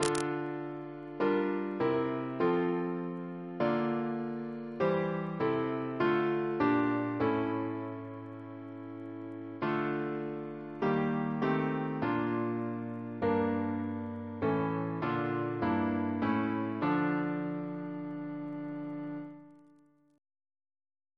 Double chant in D minor Composer: Henry Smart (1813-1879) Reference psalters: ACB: 219